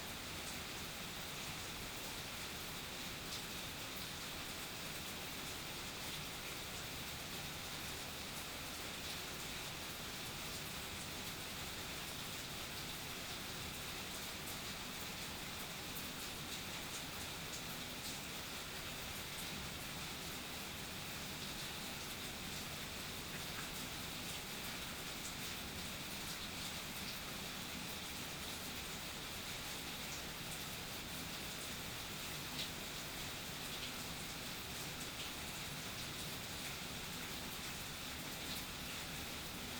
Weather Evening Suburbs Rainfall Concrete 03 BH2N_ambiX.wav